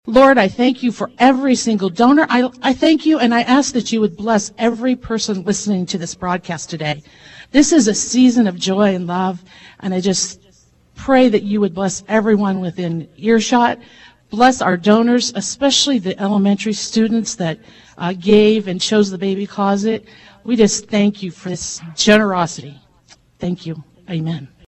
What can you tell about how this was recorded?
There were signs of a massive Match Day before Tuesday’s official check presentation ceremony — but most people on hand were totally shocked at the individual check amounts and the final total announced at Flinthills Mall as part of live coverage on KVOE.